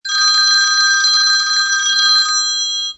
PIXABAY-phone-ringing-48238.mp3